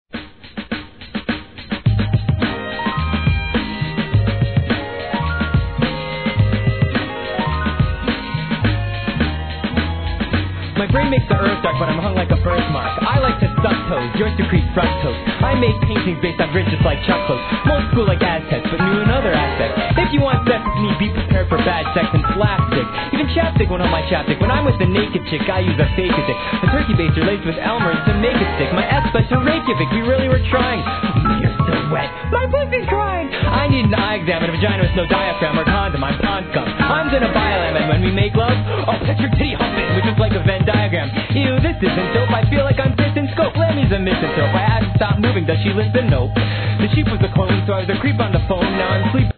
HIP HOP/R&B
心地よく分かりやすいネタ使い、コミカルなプロダクションで要クリック!!